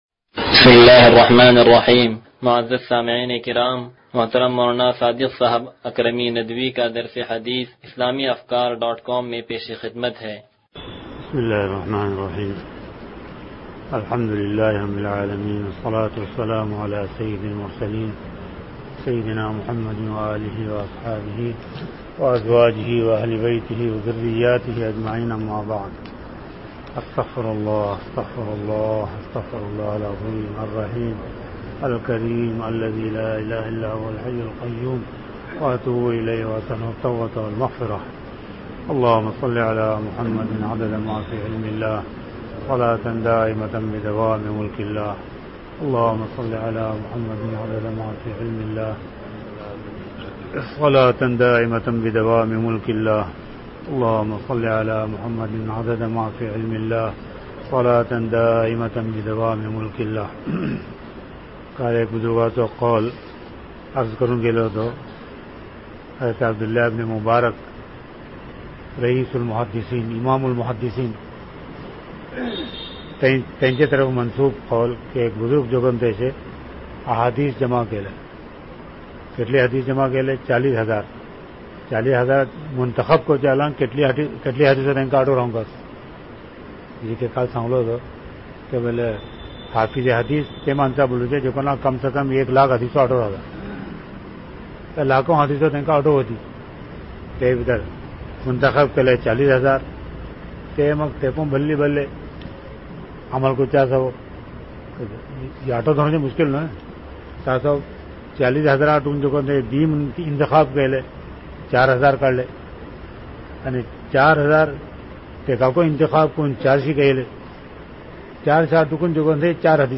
درس حدیث نمبر 0083
(تنظیم مسجد)